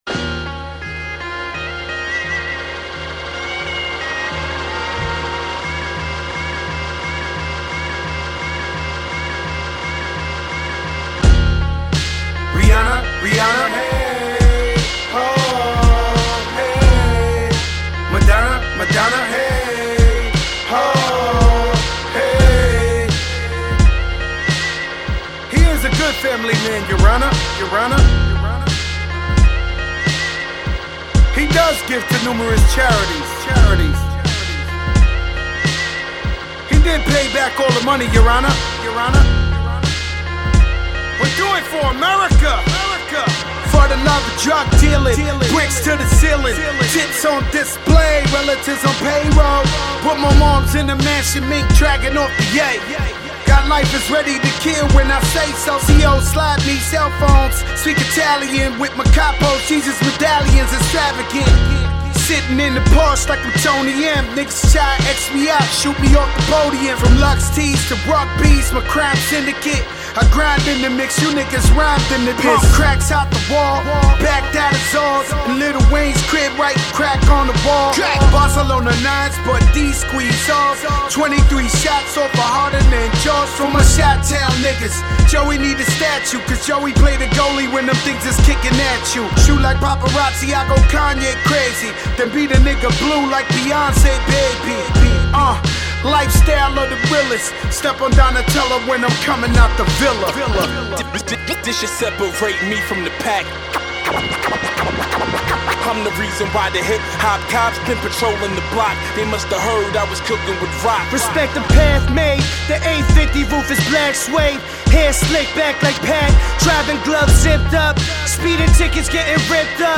the classic scratches are in effect.